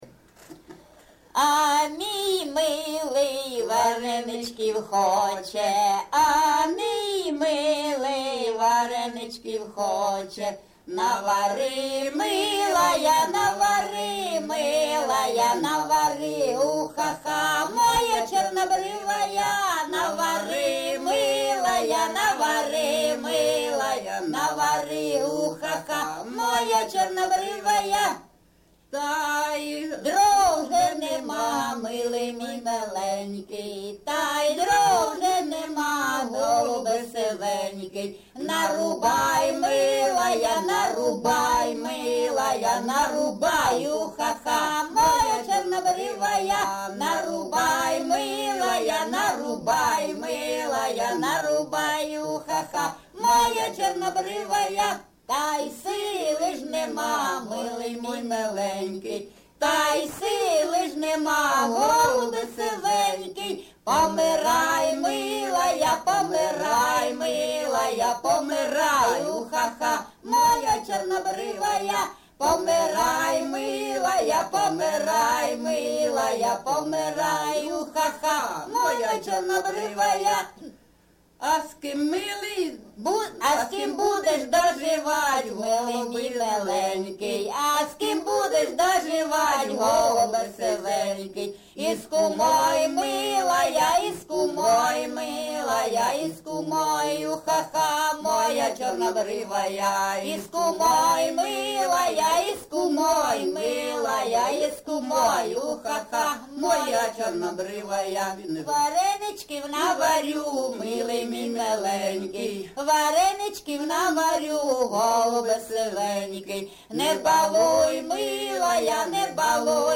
ЖанрПісні з особистого та родинного життя, Жартівливі
Місце записум. Сіверськ, Артемівський (Бахмутський) район, Донецька обл., Україна, Слобожанщина